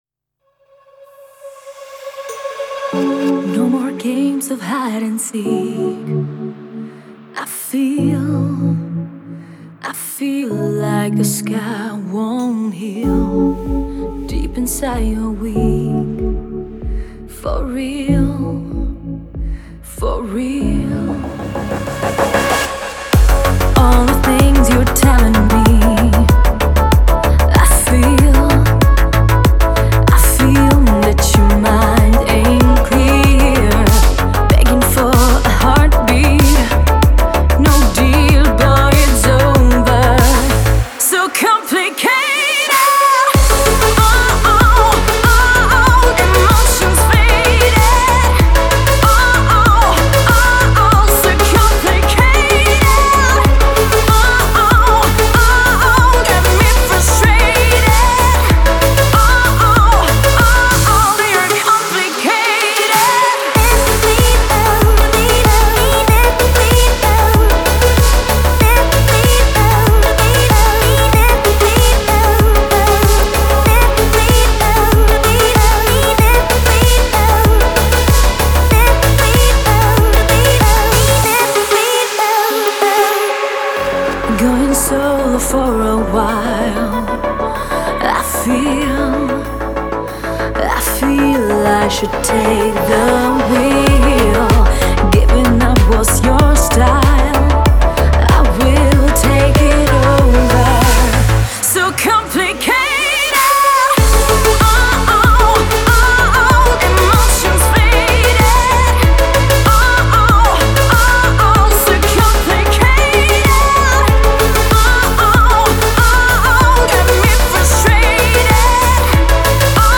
энергичная поп-панк песня